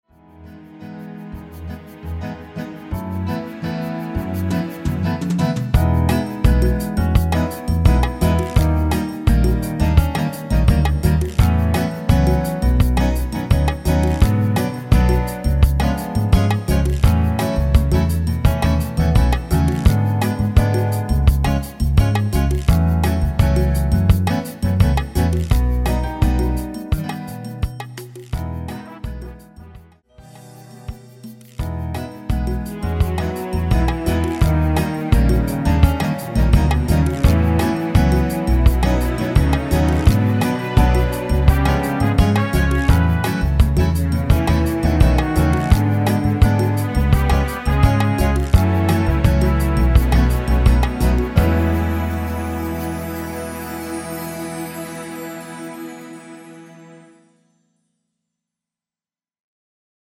1절만 편곡한 MR 입니다.
◈ 곡명 옆 (-1)은 반음 내림, (+1)은 반음 올림 입니다.
앞부분30초, 뒷부분30초씩 편집해서 올려 드리고 있습니다.
중간에 음이 끈어지고 다시 나오는 이유는